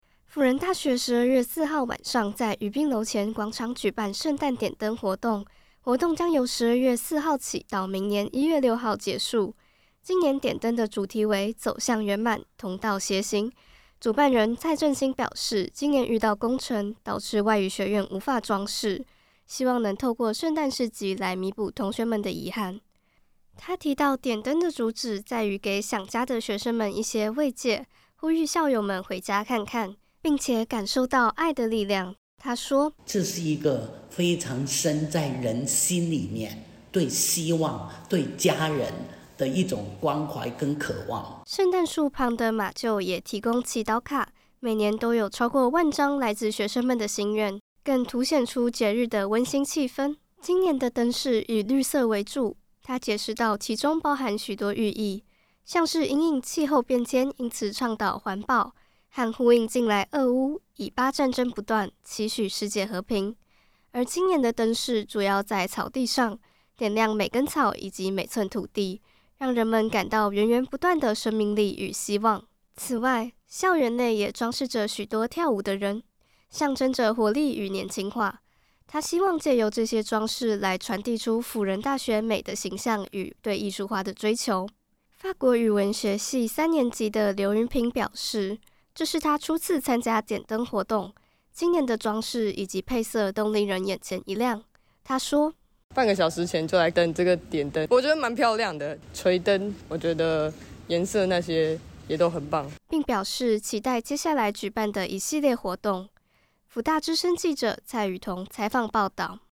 輔仁大學十二月四號晚上在于斌樓前廣場舉辦「聖誕點燈」活動，主題為「走向圓滿，同道偕行」，邀請學校師生一起見證一年一度聖誕系列活動開跑。儘管天氣不佳，仍有數百名學生到場等待。